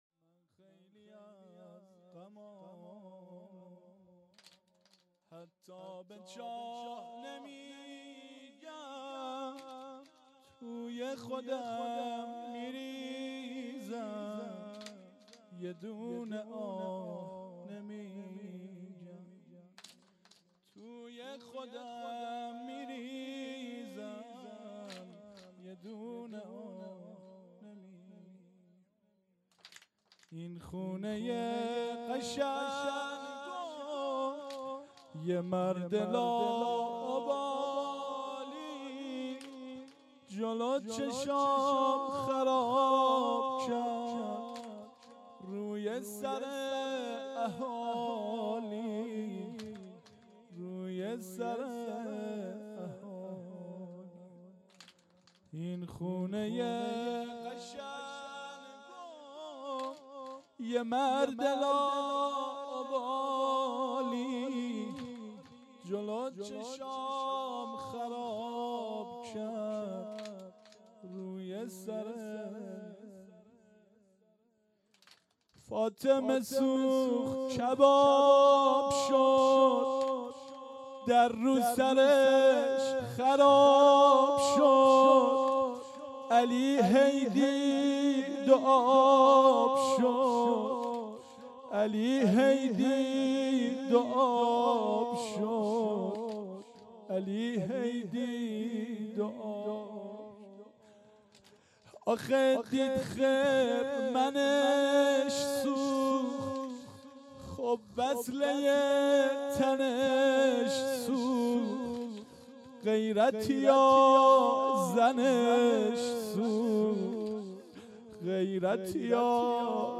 شب اول روضه